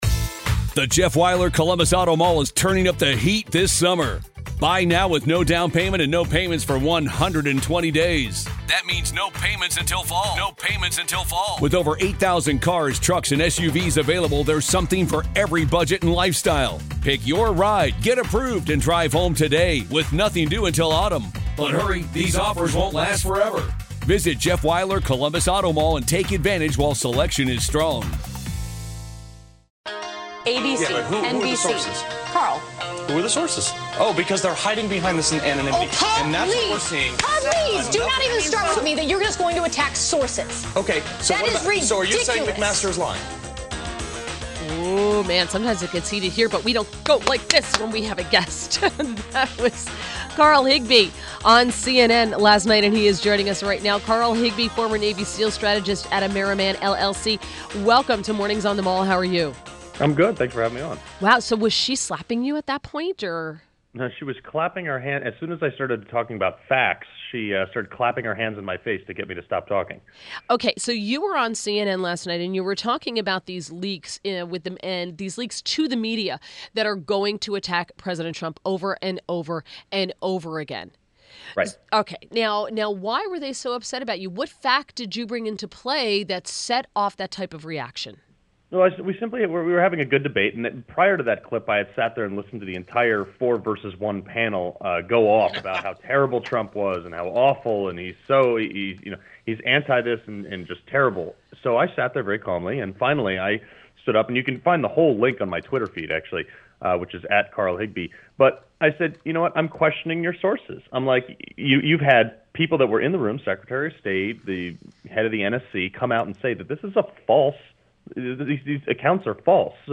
WMAL Interview - CARL HIGBIE 05.17.17
CARL HIGBIE - former Navy SEAL and strategist at AmeriMan LLC TOPIC: Leaks in the media attacking Trump